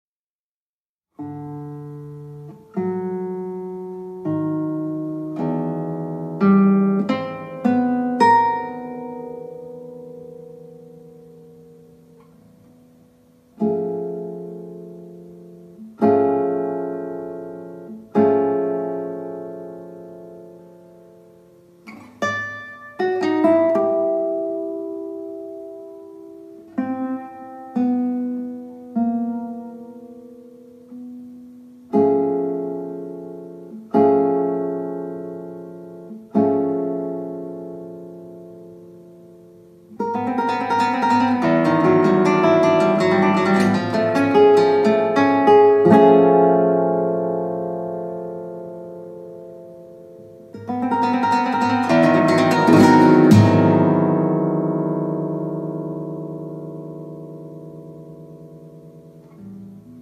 Tranquillo   0:56